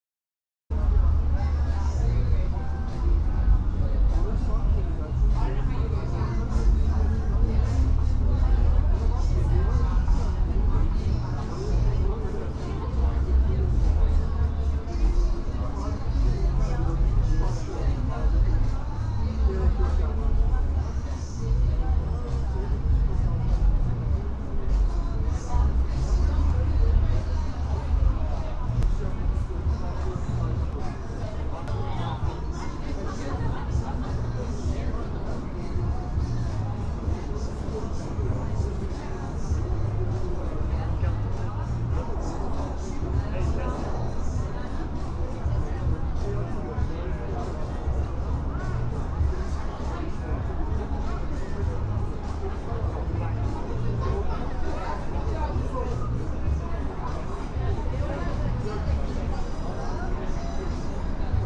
Live from Soundcamp: Stop the Eviction (Audio) Nov 15, 2023 shows Live from Soundcamp Live audio from Old Paradise Yard, Waterloo. Gathering against eviction.